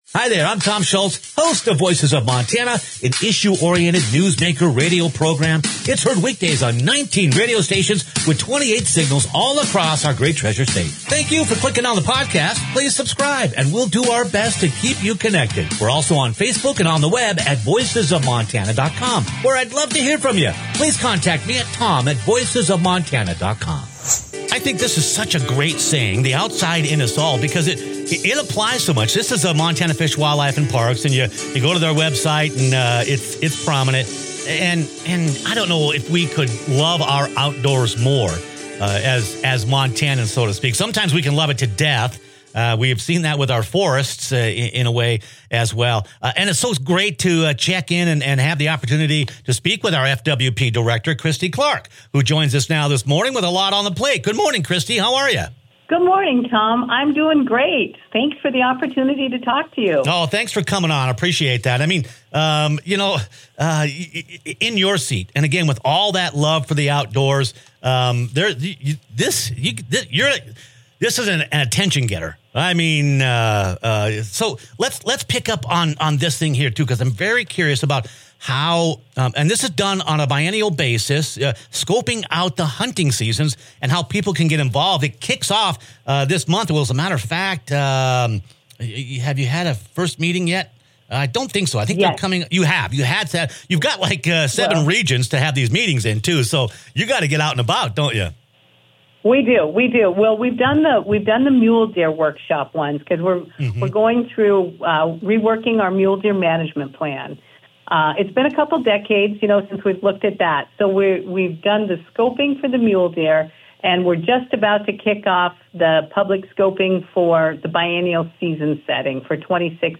Hunting regulations in Montana can spark debate and curiosity among hunters—and now’s your chance to shape them. Click on the podcast as FWP Director Christy Clark discusses the upcoming “season setting” process, a statewide review that happens every other year, where Montana Fish, Wildlife & Parks gathers input on everything from minor tweaks to major